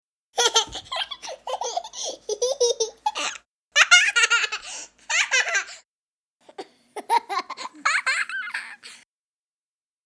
天真的小孩笑声音效_人物音效音效配乐_免费素材下载_提案神器